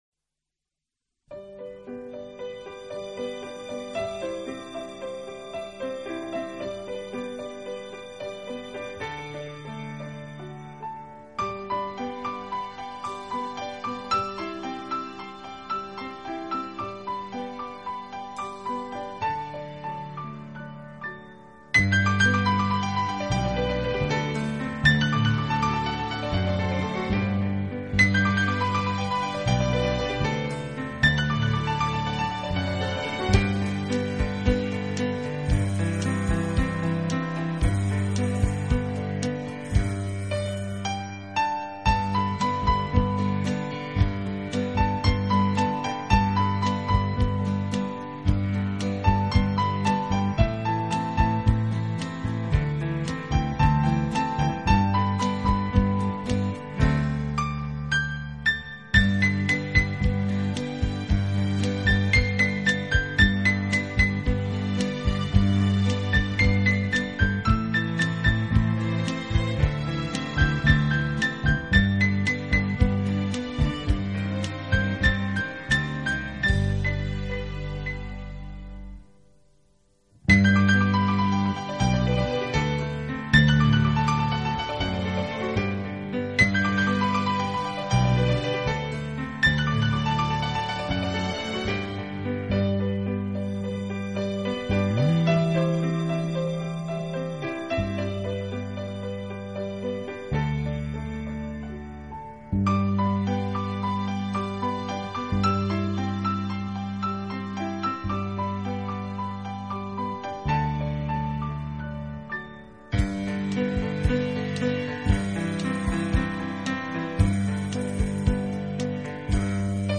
本套CD全部钢琴演奏，